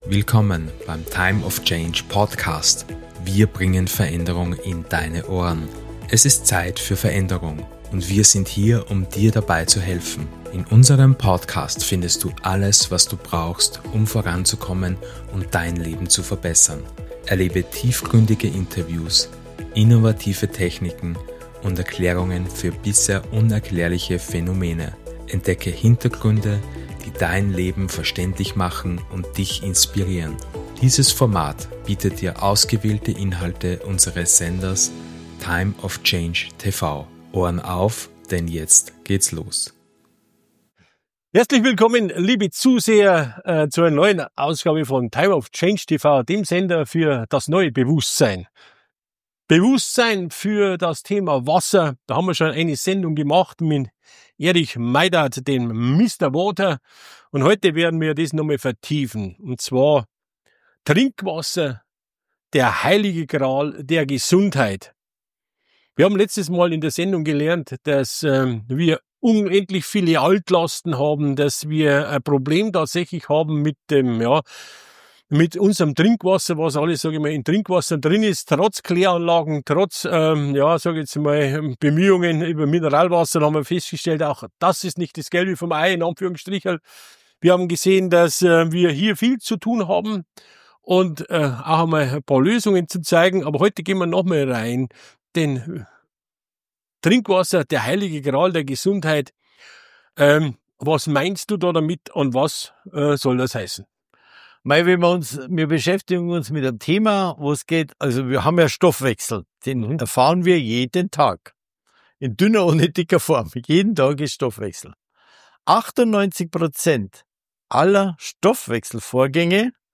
Direkt hier nachhören Dieses Interview kann Deine Sichtweise auf Wasser grundlegend verändern.